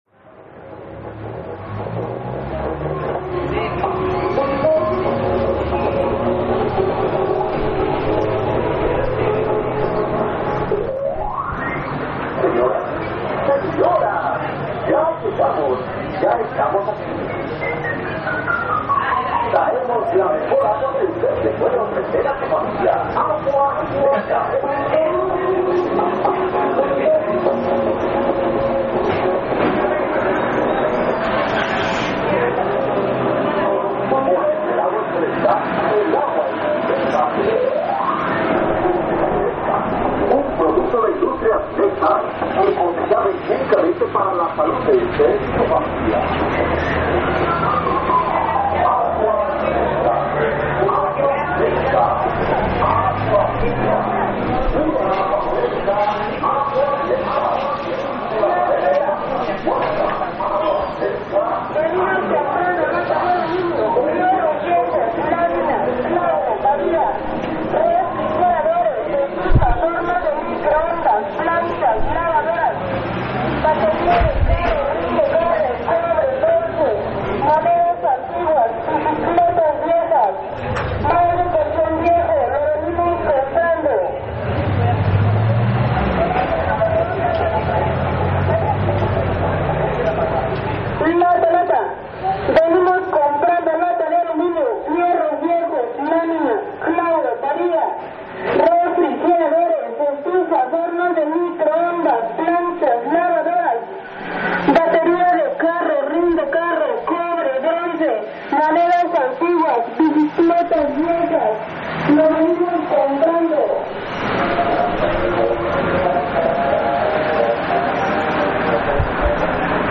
Compradores y vendedores ambulantes
Es de mañana, los compradores y vendedores ambulantes forman parte del paisaje de cada ciudad, en los aparatos de sonido se escucha no sólo el lexico sino las preferencias musicales.
Lugar: San Cristóbal de Las Casas, Chiapas; Mexico.
Equipo: Grabadora Sony ICD-UX80 Stereo